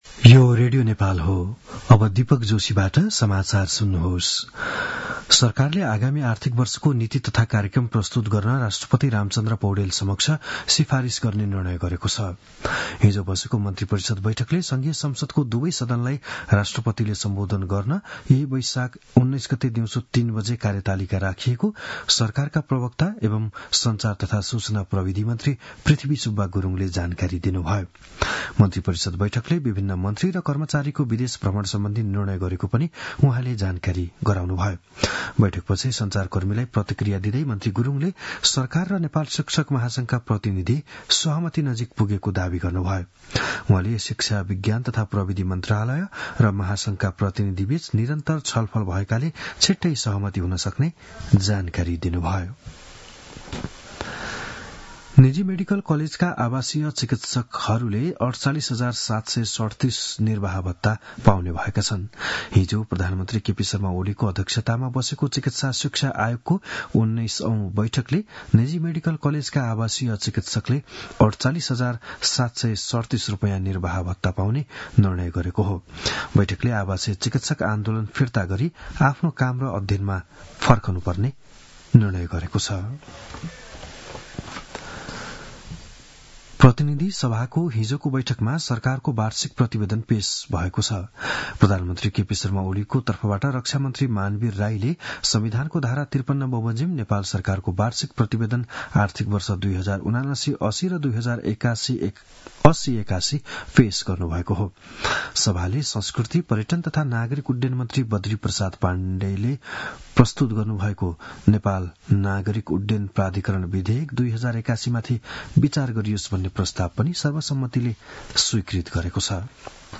बिहान ११ बजेको नेपाली समाचार : १६ वैशाख , २०८२
11-am-news-1.mp3